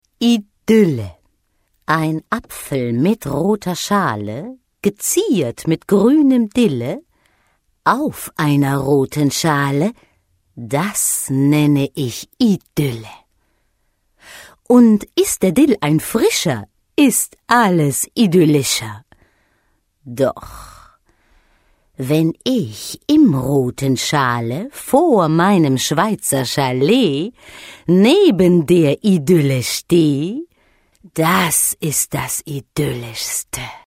Kein Dialekt
Sprechprobe: Industrie (Muttersprache):
female voice over talent german